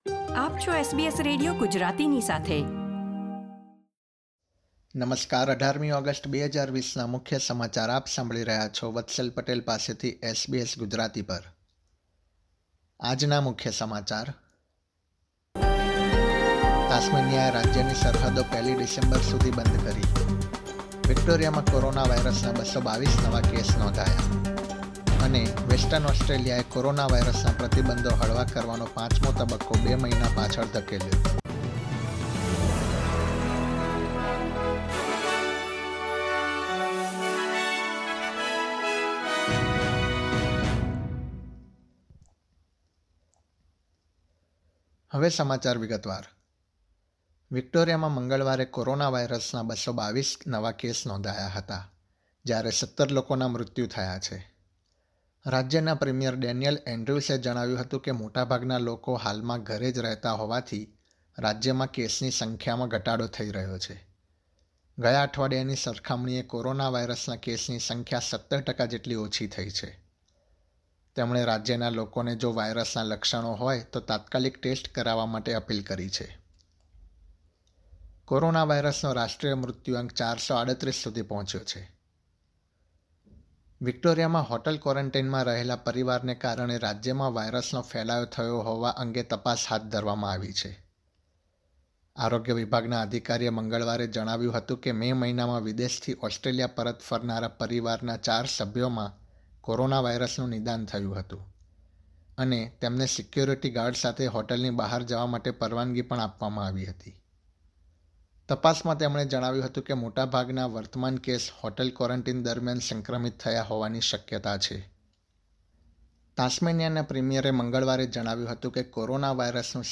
SBS Gujarati News Bulletin 18 August 2020
gujarati_1808_newsbulletin.mp3